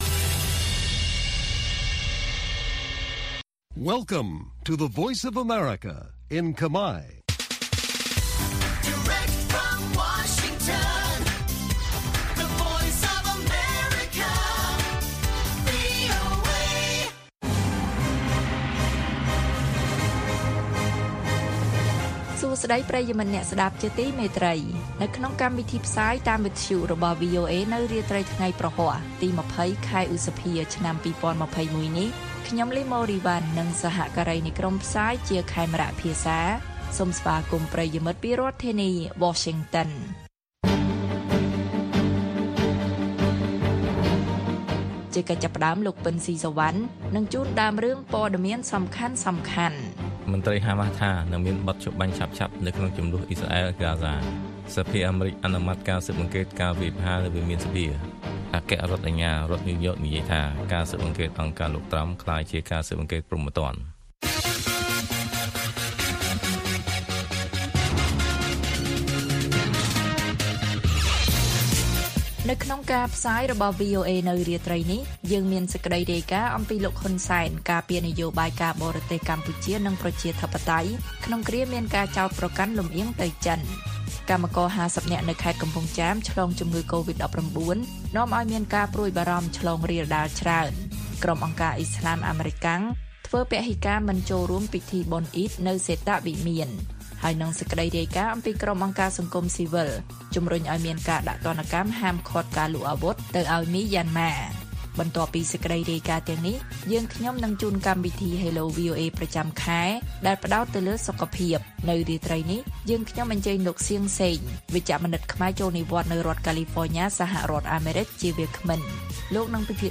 ព័ត៌មានពេលរាត្រី៖ ២០ ឧសភា ២០២១